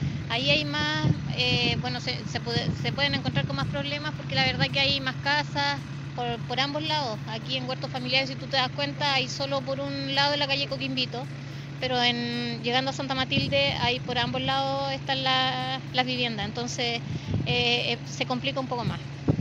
En el lugar, la concejal independiente de la comuna, Nelda Gil, destacó el riesgo que este incendio forestal significó para la población Santa Matilde.